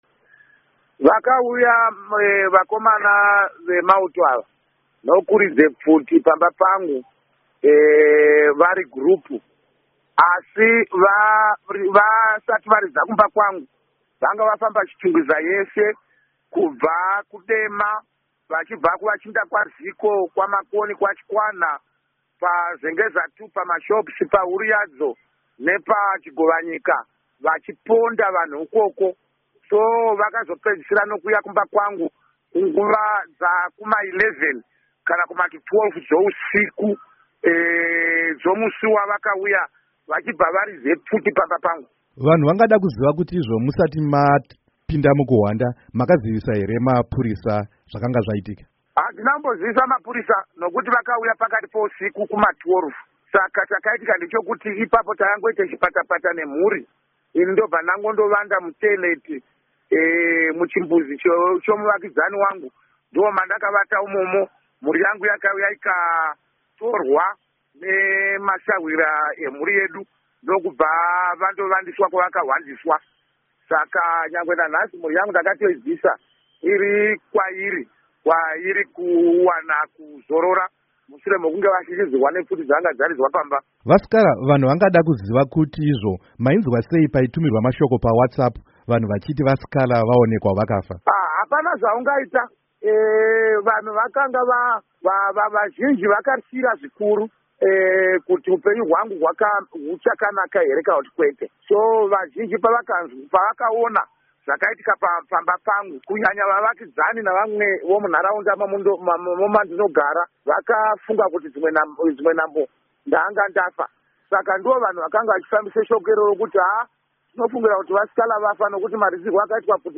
Hurukuro naVaJob Sikhala